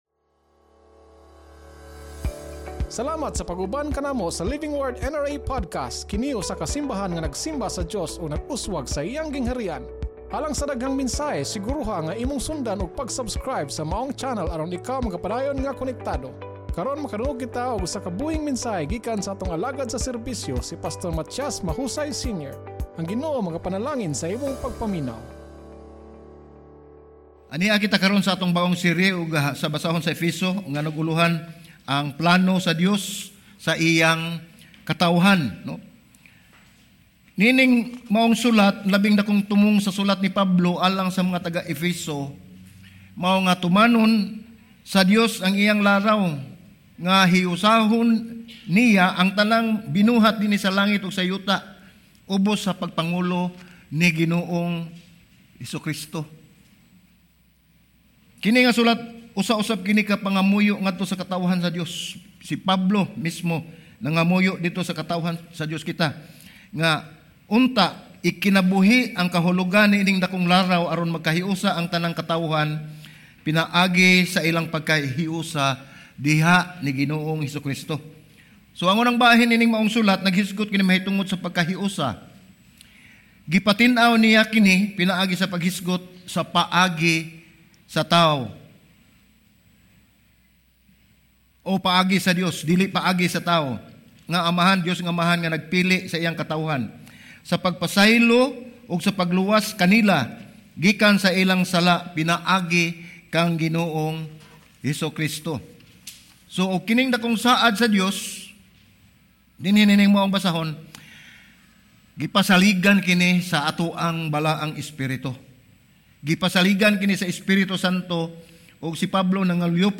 Sermon Title: IMPORTANTE ANG IMONG PAGKATAWO (IDENTITY)